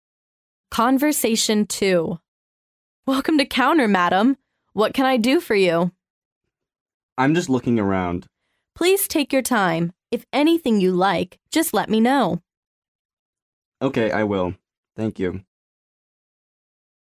����Ӣ����� ��7��:����Ӵ�(2) ����:2012-10-11 ��Ƶ���� Conversation 2 A: Welcome to counter, madam.